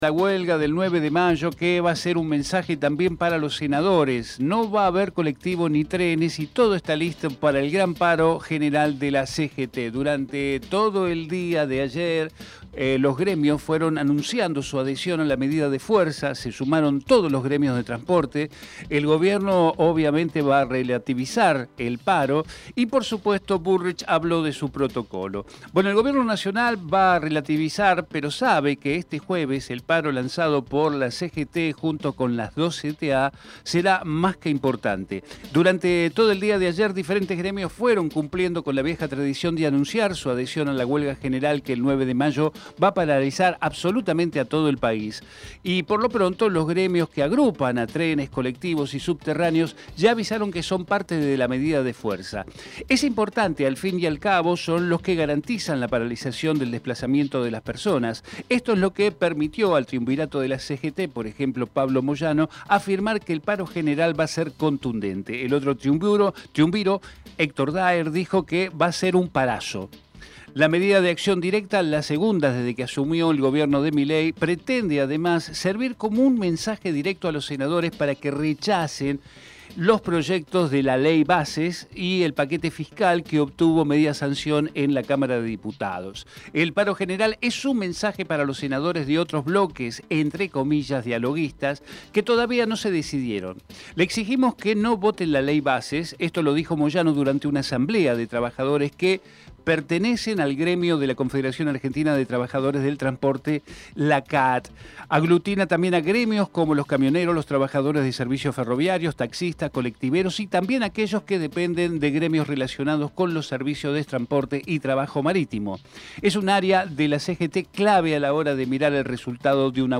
Compartimos con ustedes la entrevista realizada en Territorio Sur